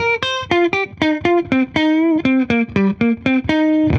Index of /musicradar/dusty-funk-samples/Guitar/120bpm
DF_70sStrat_120-C.wav